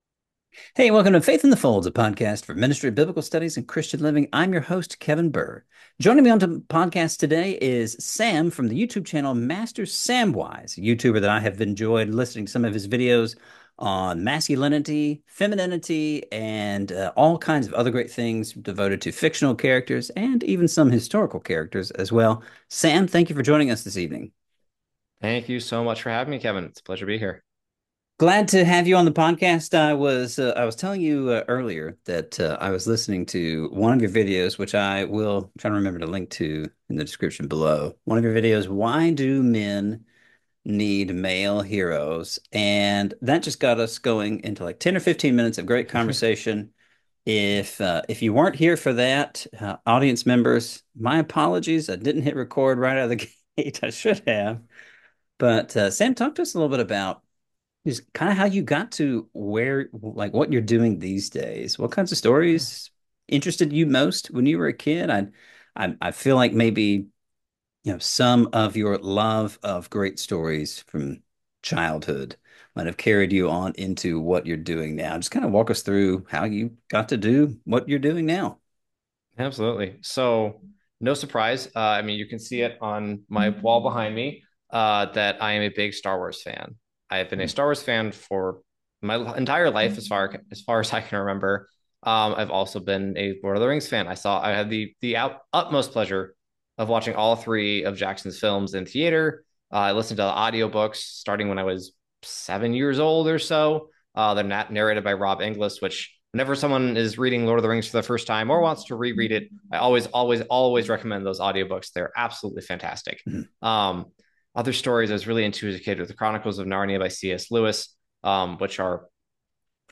We referenced this video early on in the interview: